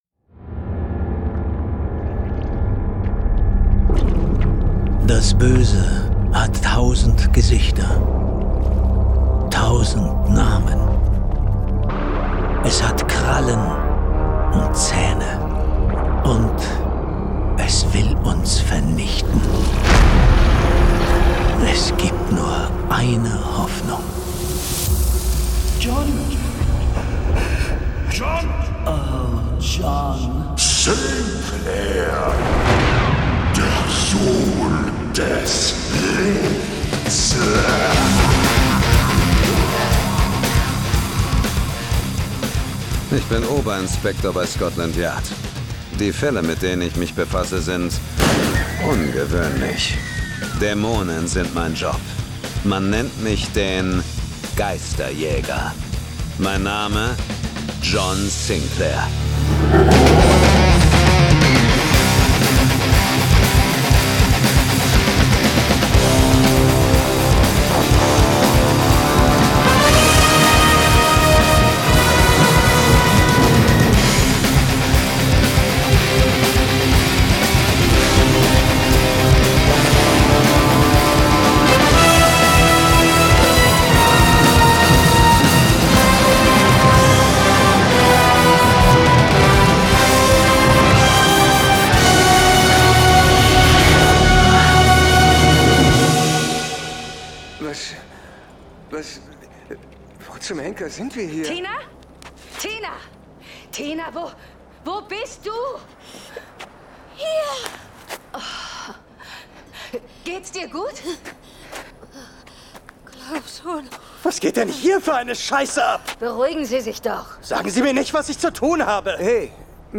Jason Dark (Autor) Dietmar Wunder , diverse (Sprecher) Audio-CD 2026 | 1.